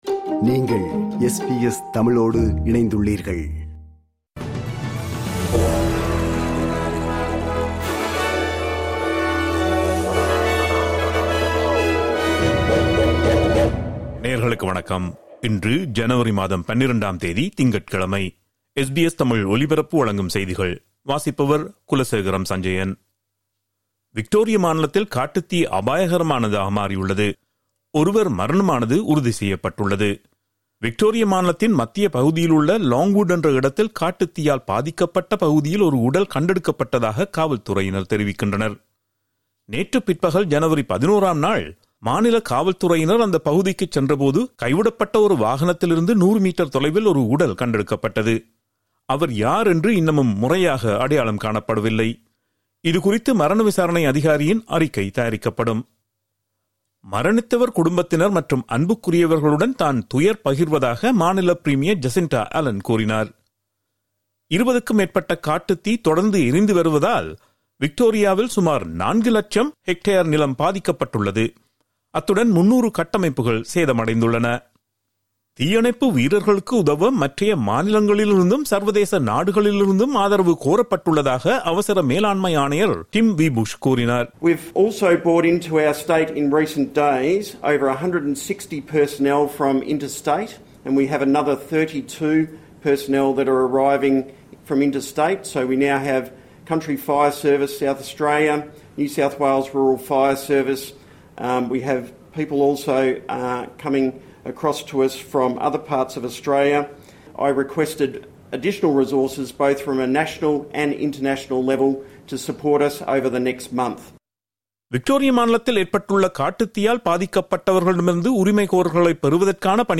இன்றைய செய்திகள்: 12 ஜனவரி 2026 - திங்கட்கிழமை
SBS தமிழ் ஒலிபரப்பின் இன்றைய (திங்கட்கிழமை 12/01/2026) செய்திகள்.